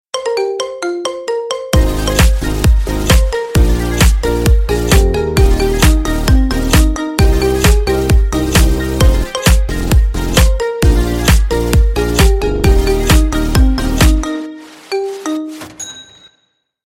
Рингтоны Без Слов
Рингтоны Ремиксы